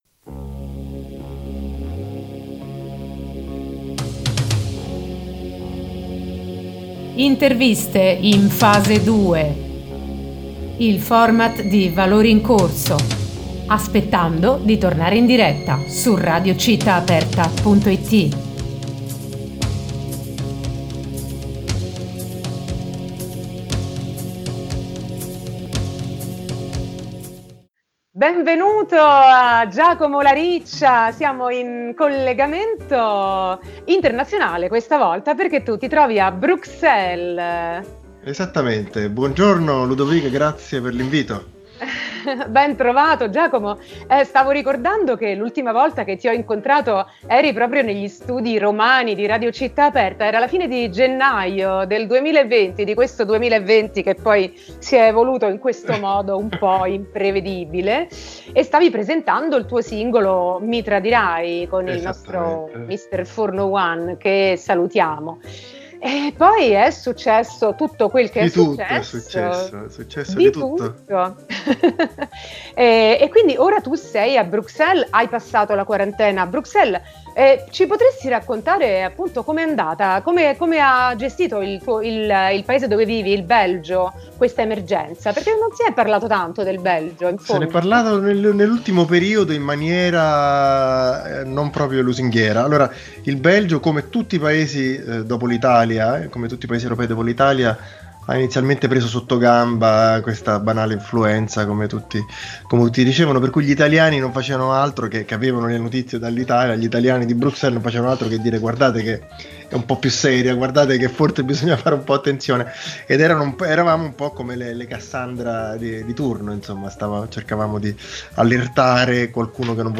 Intervista
in collegamento Skype da Bruxelles